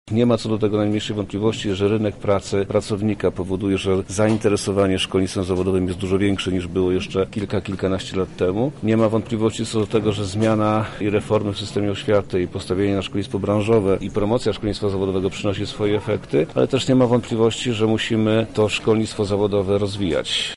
Konferencja zainaugurowana przez ministra edukacji ma zapoczątkować współpracę pomiędzy oświatą a lokalnymi branżami i instytucjami.
• mówi minister edukacji i nauki Przemysław Czarnek.